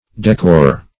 Search Result for " decore" : The Collaborative International Dictionary of English v.0.48: decore \de*core"\ (d[-e]*k[=o]r"), v. t. [Cf. F. d['e]corer.